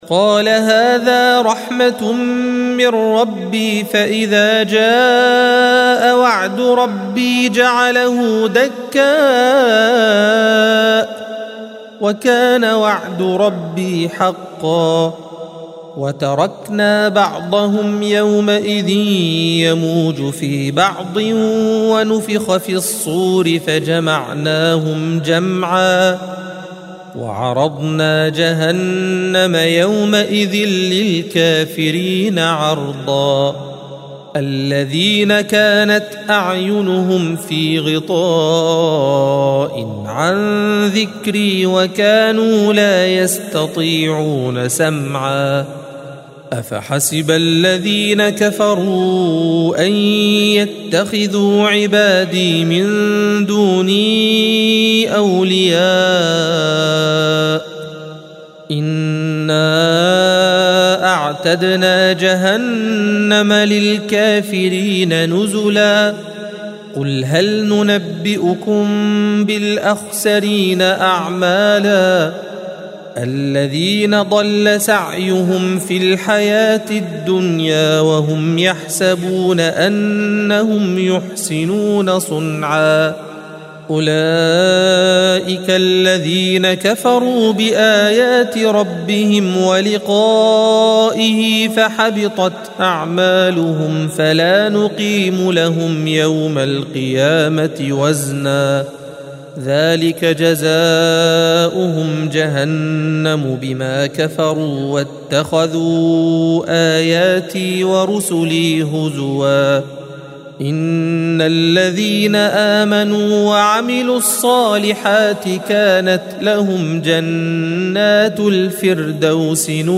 الصفحة 304 - القارئ